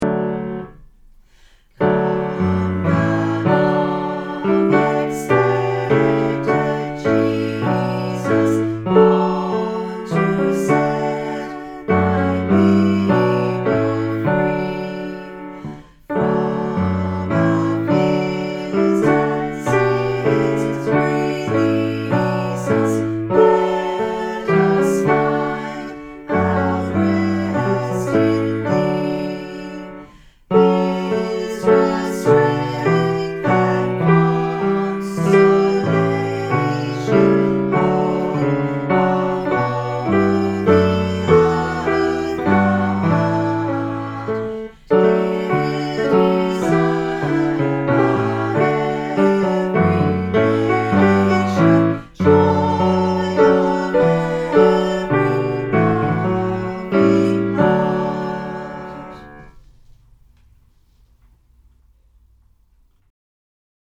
Vox Populi Choir is a community choir based in Carlton and open to all comers.